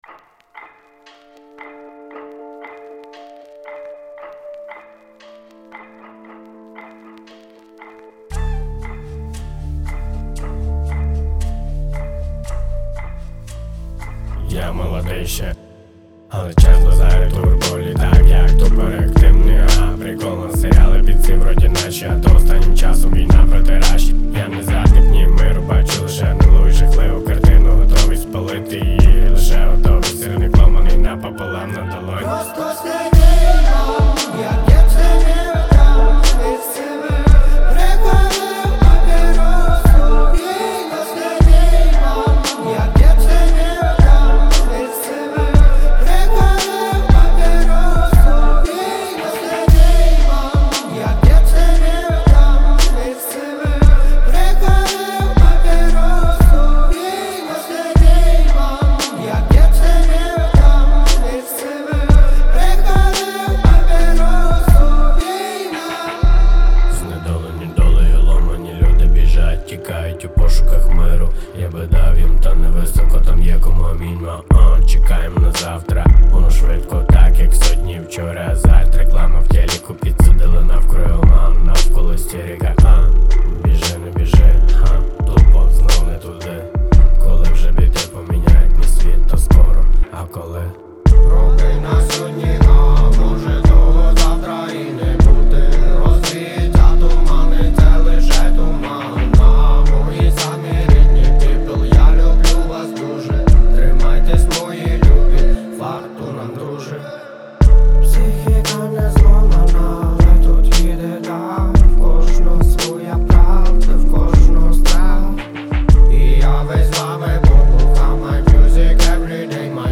• Жанр: Hip-Hop